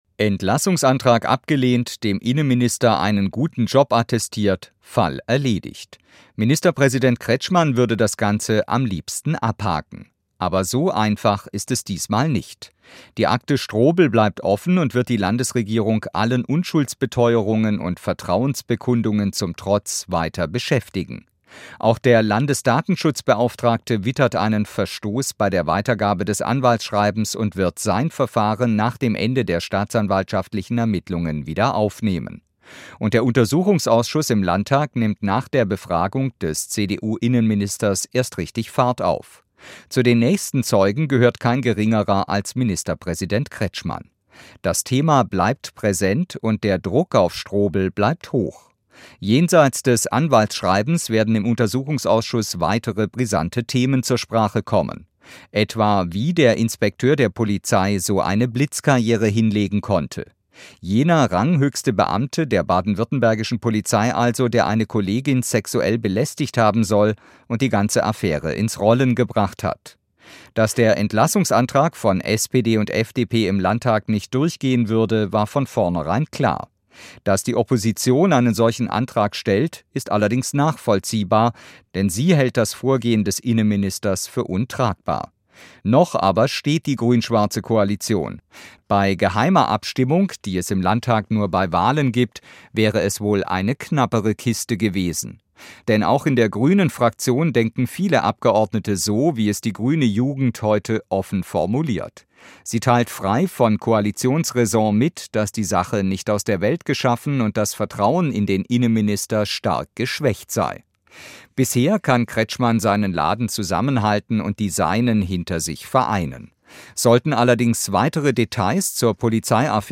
Kommentar: Strobl bleibt Minister auf Bewährung